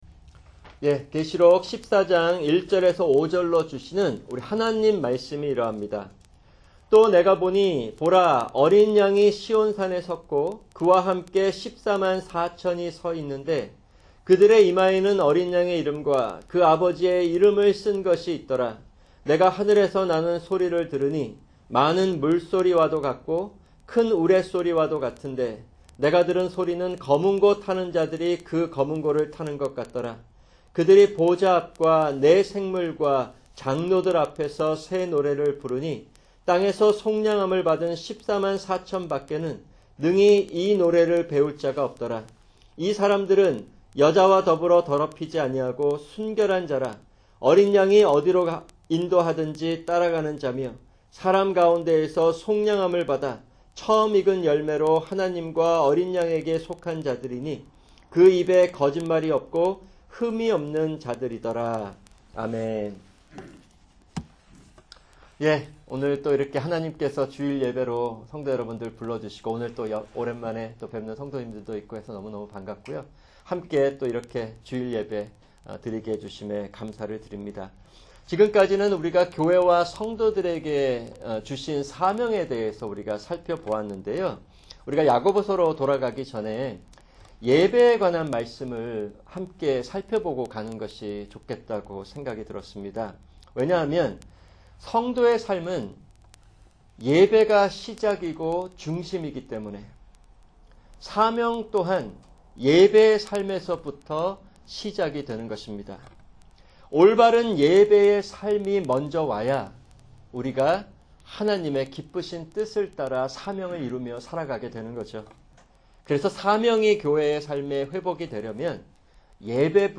[주일 설교] 계시록 15:2-4(3)